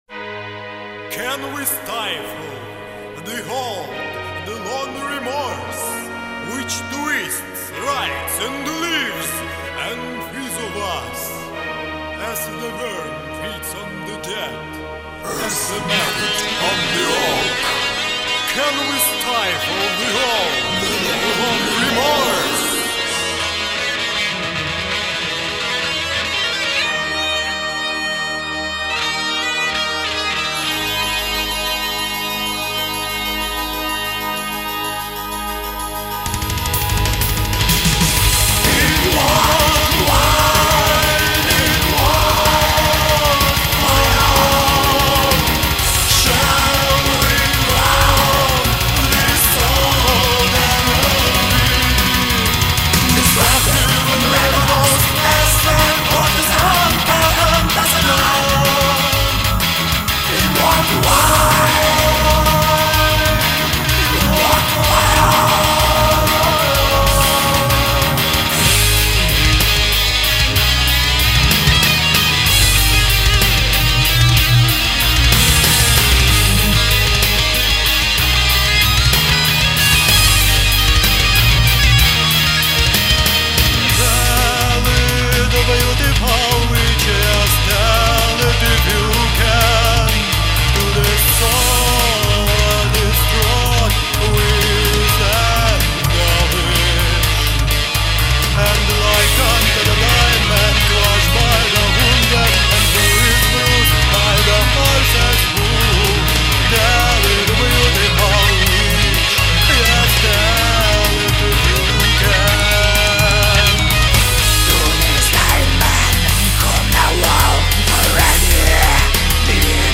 он же писал все клавишные основы.
гитары
а вокал